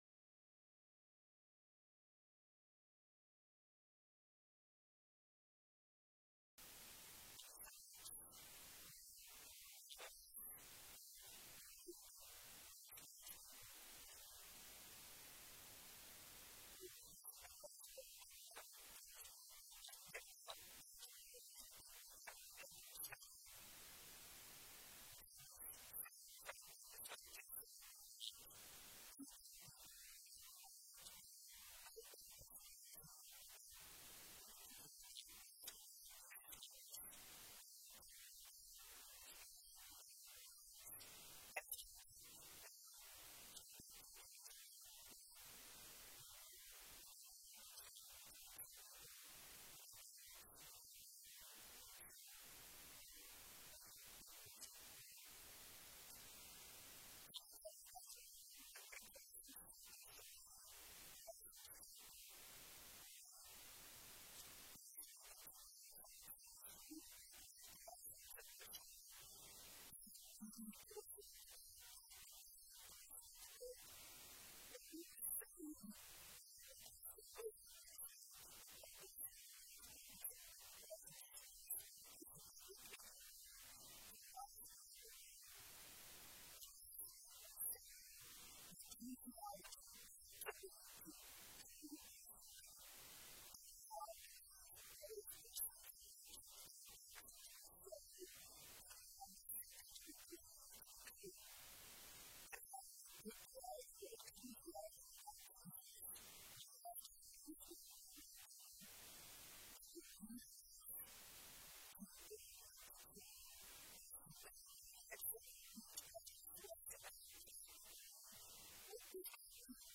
Worship Guide Sermon Transcript Scripture Reference: Colossians 3:5-11